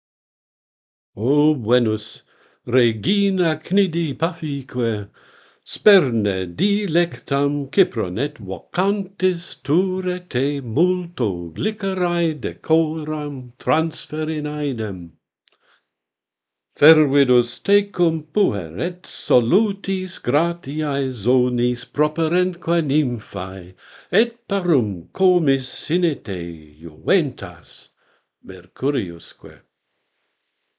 A prayer to Venus - Pantheon Poets | Latin Poetry Recited and Translated